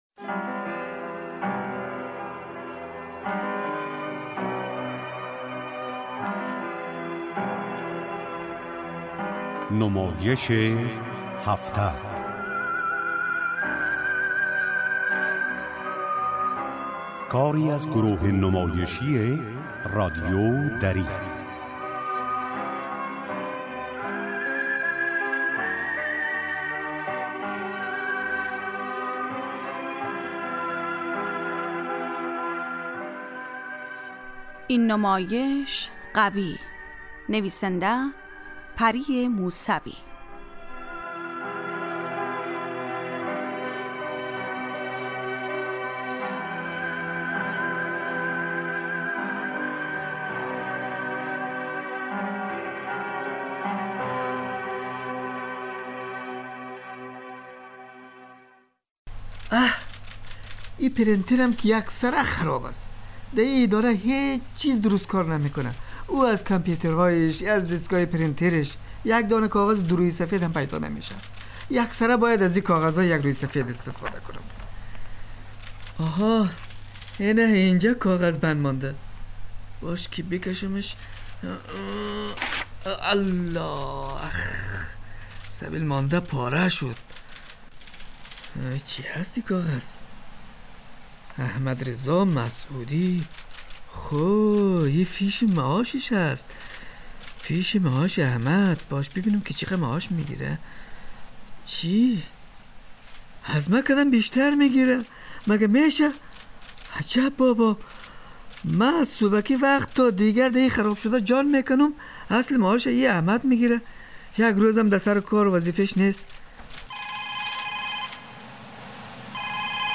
نمایش هفته